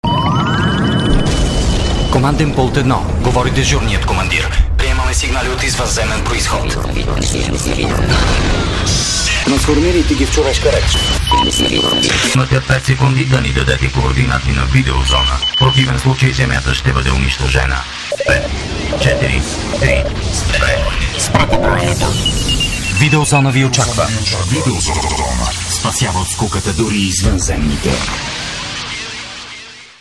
Video Zona radio commercial spot